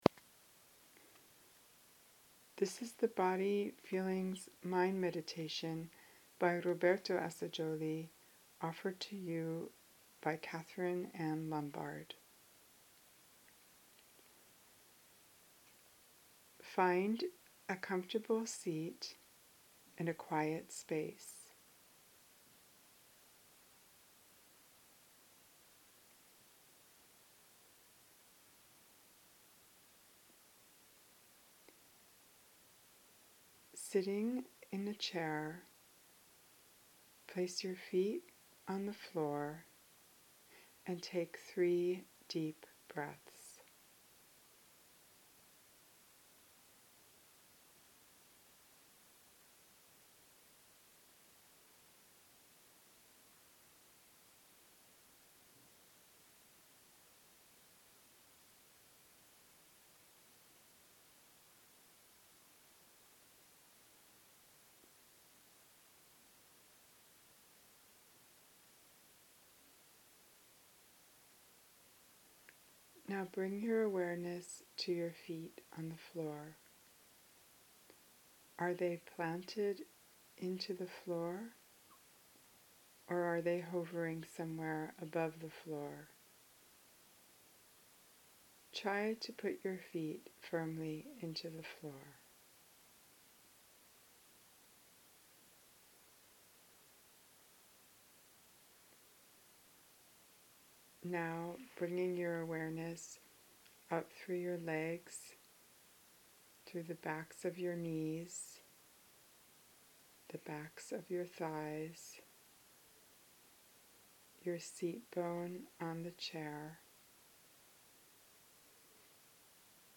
Feel free to download this mp3 recording of the 15-minute meditation.